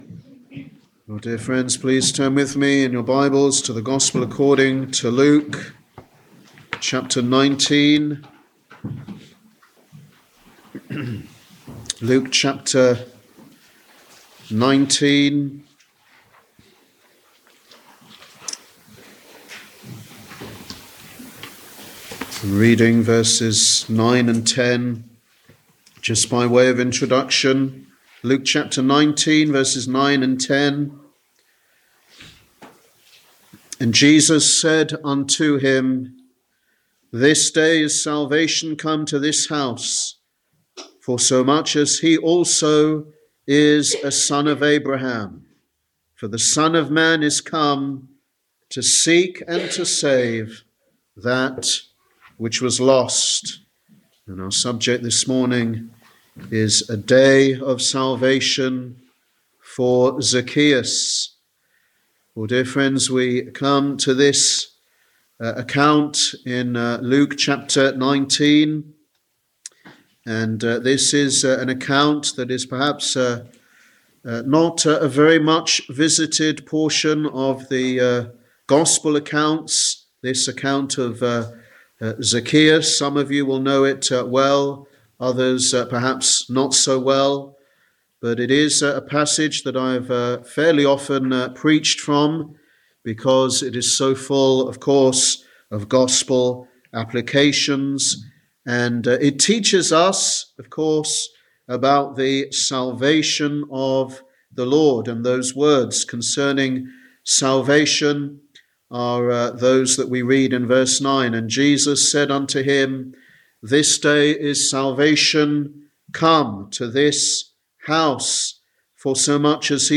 Sunday Evangelistic Service